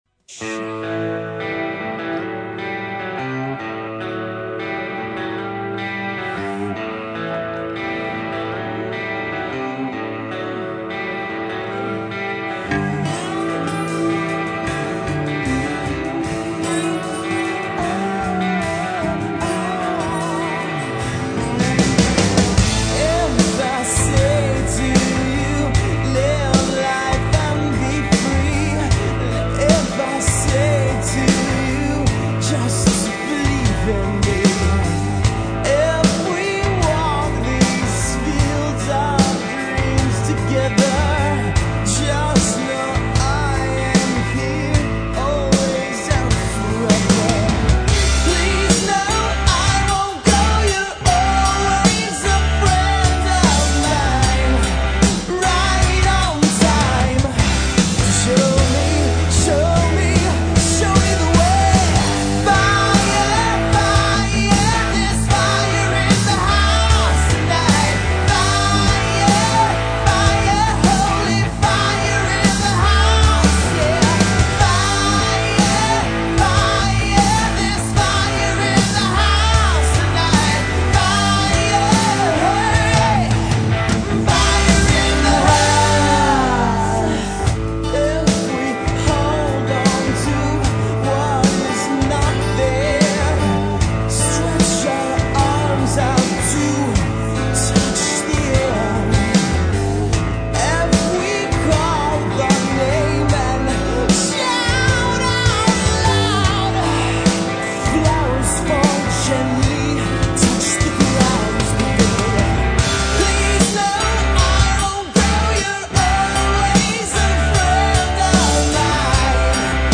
No thats a real Rocket.